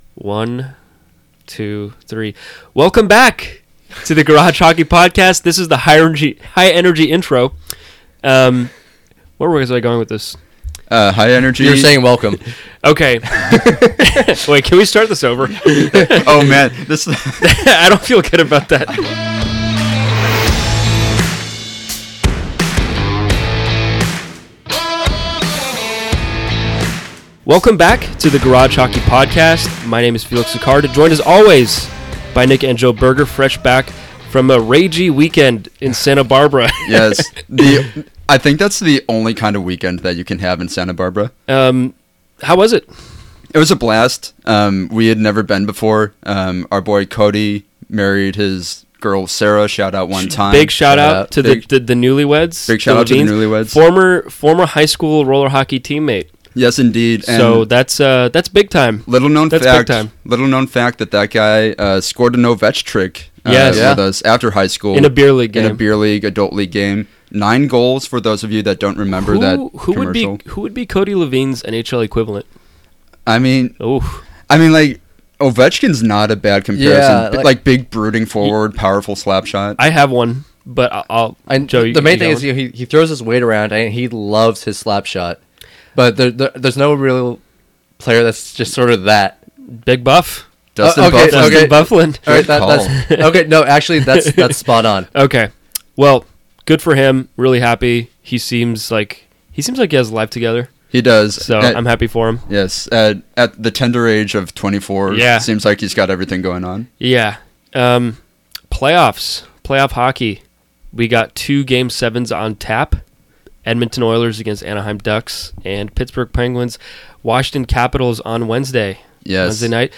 in the garage